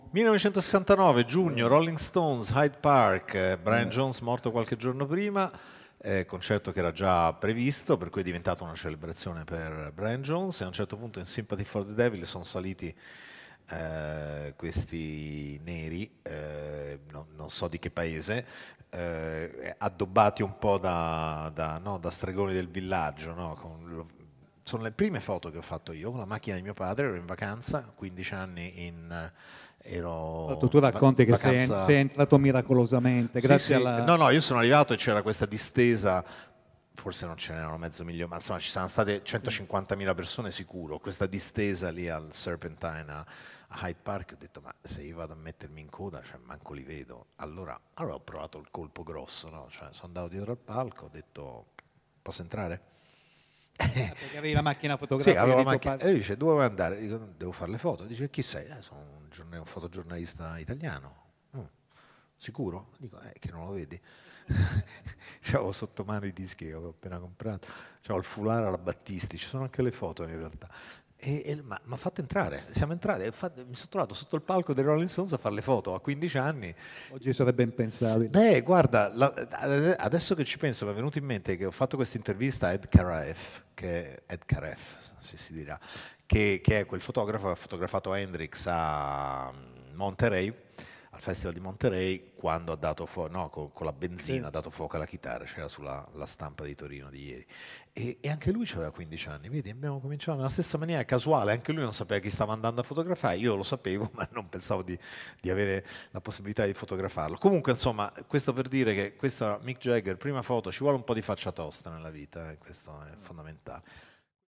Direttamente dalla sua voce, l’esordio del giovanissimo Massarini nel mondo della musica, sotto il palco di uno dei più celebri eventi della storia del rock.